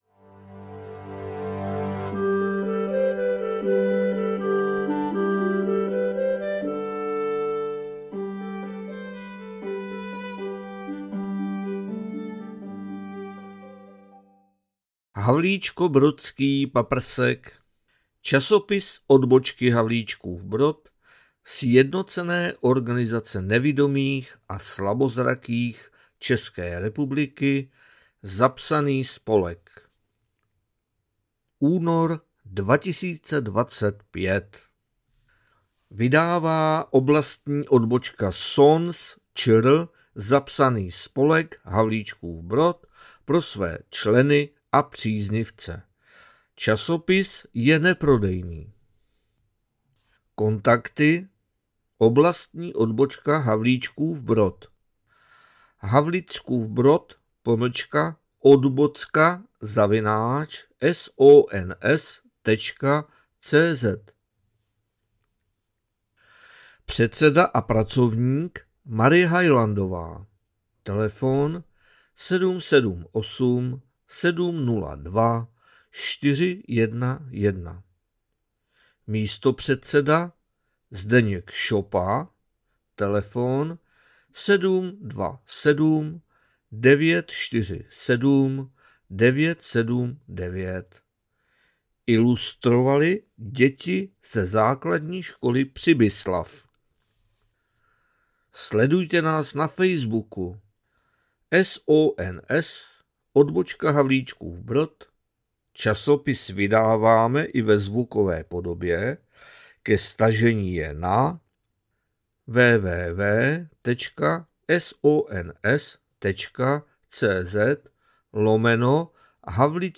SONS ČR - PAPRSEK ÚNOR 2025 NAČTENÝ